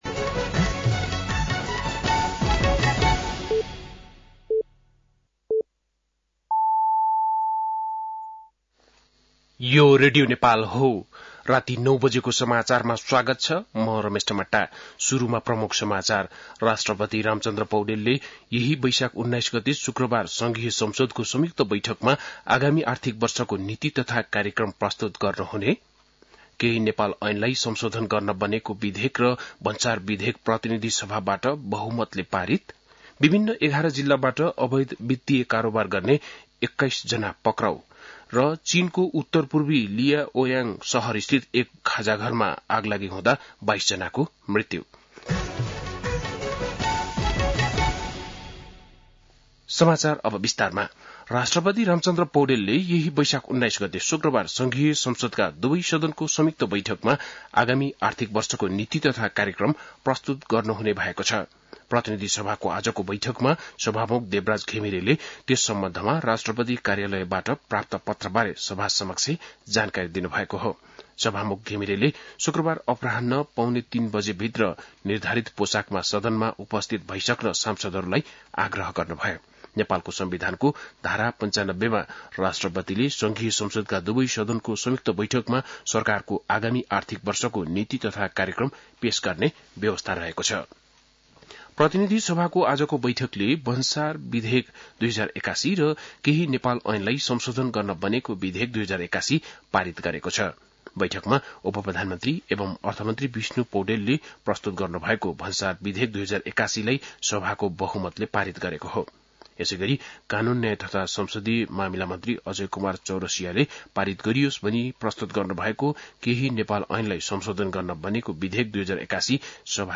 बेलुकी ९ बजेको नेपाली समाचार : १६ वैशाख , २०८२
9-Pm-Nepali-news-1-16.mp3